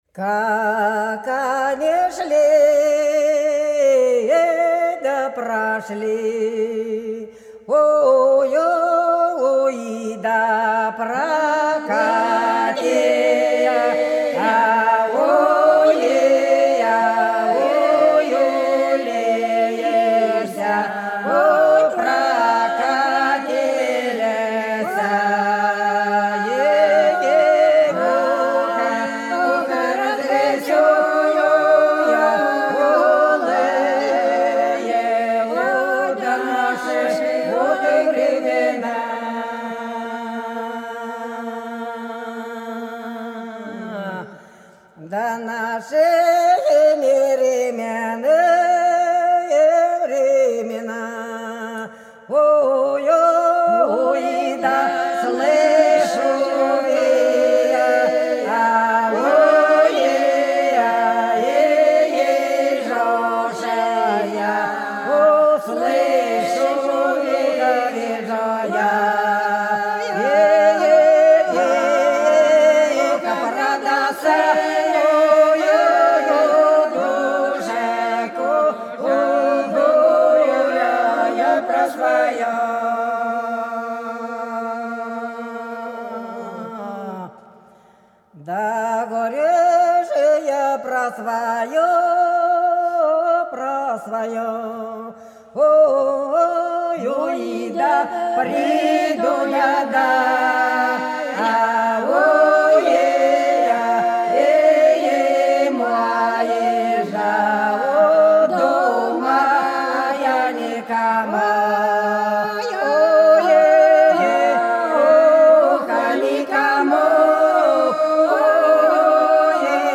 Белгородские поля (Поют народные исполнители села Прудки Красногвардейского района Белгородской области) Как они шли да прошли - протяжная